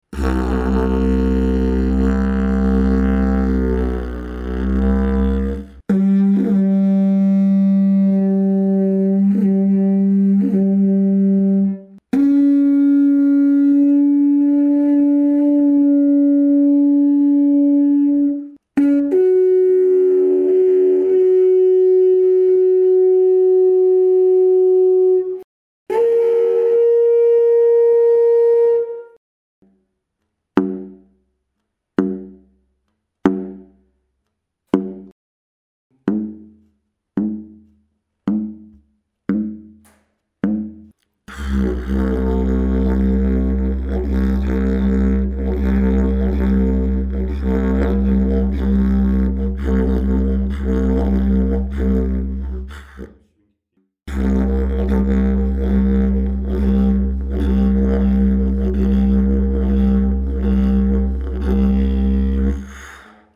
Dg548 ist ein Didgeridoo meines Modells 055 gestimmt in C2 mit dem Overblow eine Quinte über der Oktave, auf G3. Die Mensur zeigt eine bauchige Resonanzkammer mit schmalem Bell, die ihm die Klangeigenschaften eines zylindrischen Instrumentes verleiht und trotz seines tiefen Grundtones die Bauweise auf nur 163cm verkürzt. Diese Mensur macht das Didgeridoo etwas schwerer anspielbar, fördert aber eine sehr ruhige meditative Spielweise mit tiefem Grundton und ist deshalb auch nicht als Anfängerinstrument gedacht.
Dg548 Technical sound sample 01